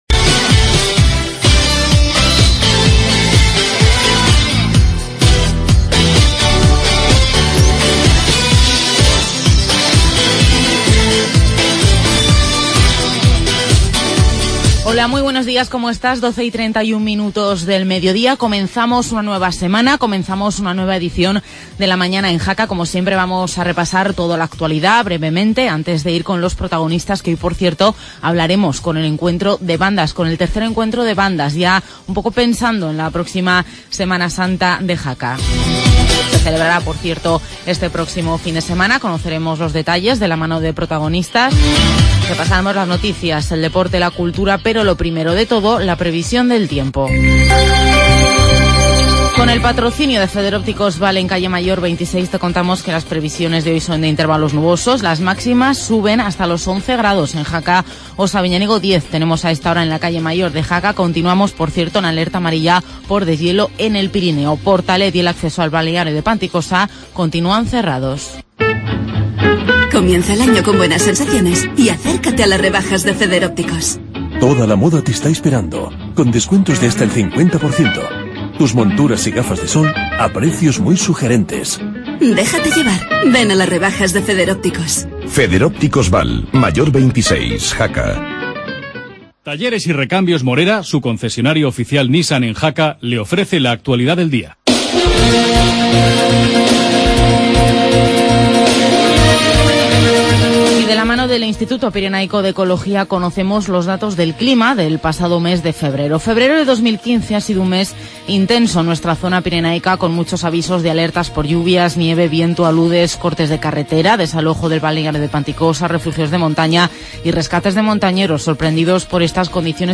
AUDIO: Actualidad de Jaca, entrevista por el III Encuentro de Bandas y debate del Jacetano.